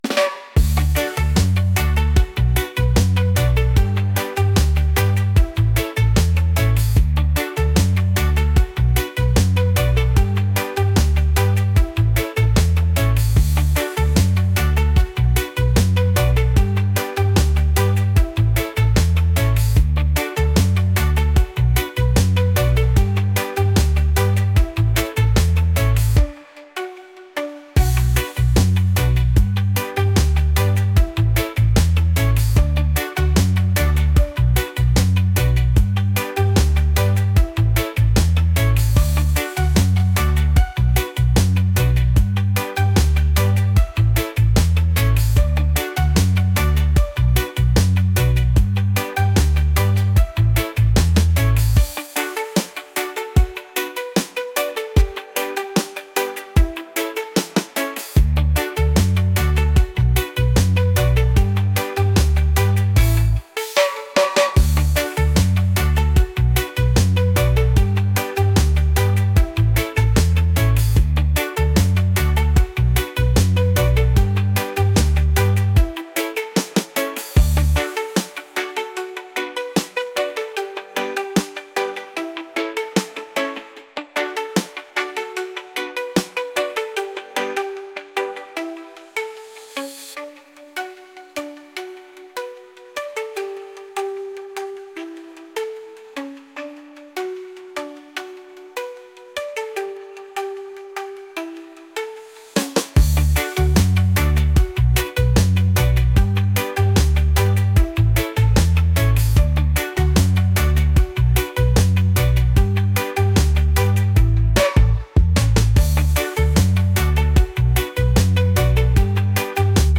reggae | pop | world